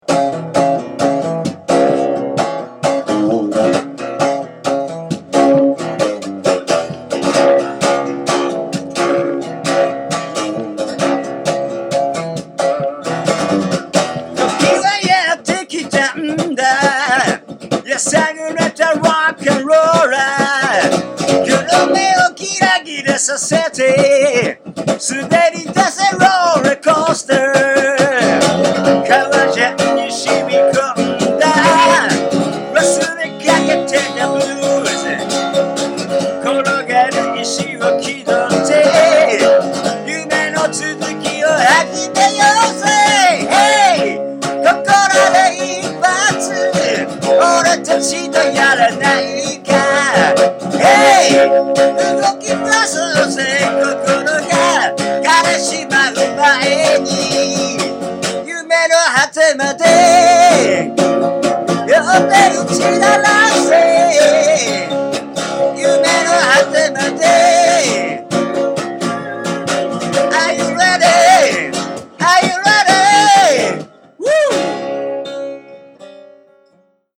生声、生音で、ちょっとだけ暑中見舞いです。